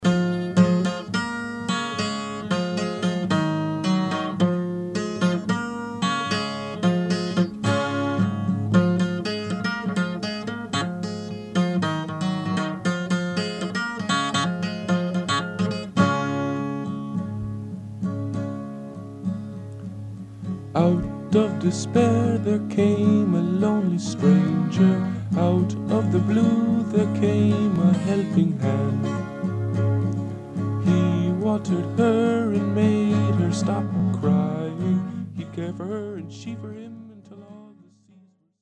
(original demo)